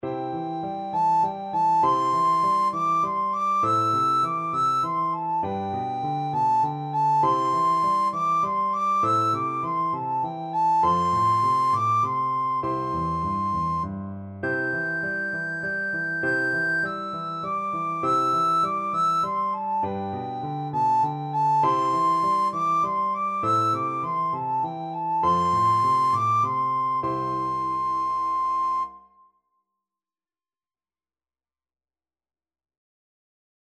Traditional Trad. Arirang (traditional Korean) Soprano (Descant) Recorder version
"Arirang" is a Korean folk song, often considered as the unofficial national anthem of Korea.
C major (Sounding Pitch) (View more C major Music for Recorder )
3/4 (View more 3/4 Music)
G6-G7
arirang_REC.mp3